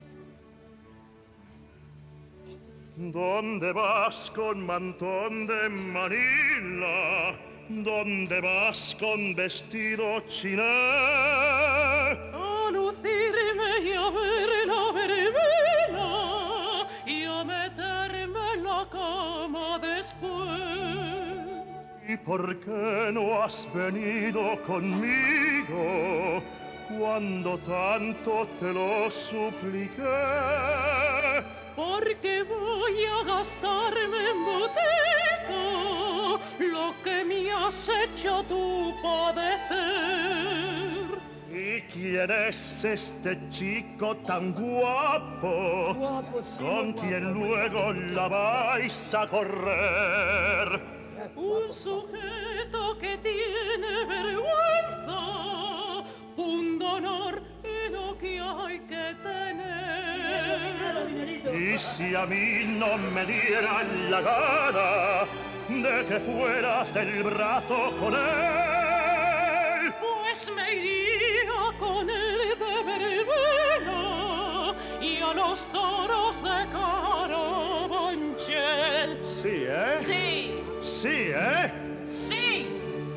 Zarzuela